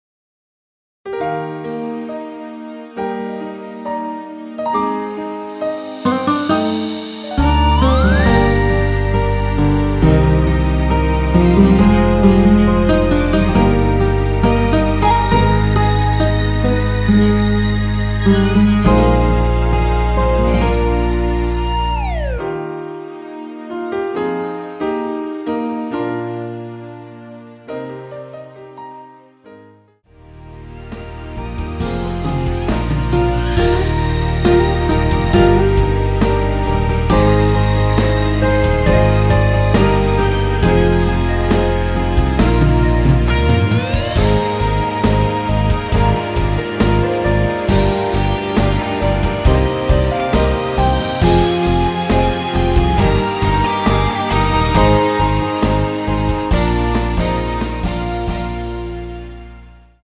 대부분의 여성분이 부르실수 있는키로 제작 되었습니다.
Eb
앞부분30초, 뒷부분30초씩 편집해서 올려 드리고 있습니다.